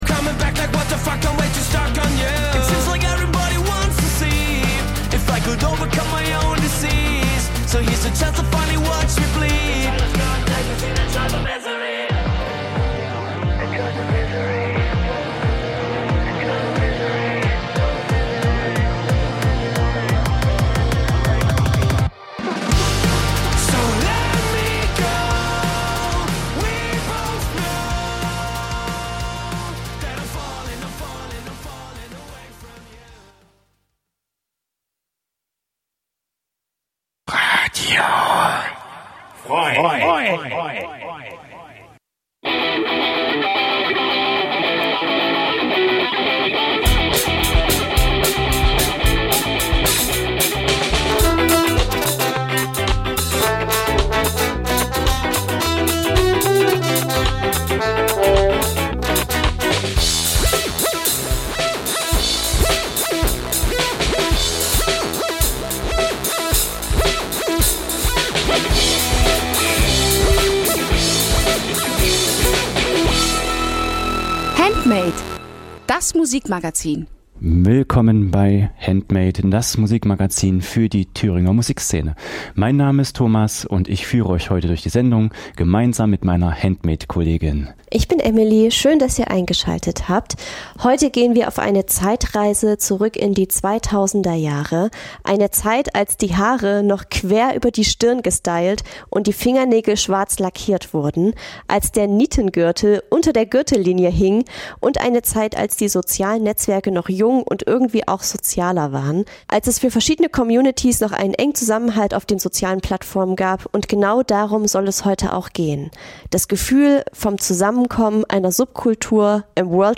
Jeden Donnerstag stellen wir euch regionale Musik vor und scheren uns dabei nicht um Genregrenzen. Ob Punk, Rap, Elektro, Liedermacher, oder, oder, oder � � wir supporten die Th�ringer Musikszene.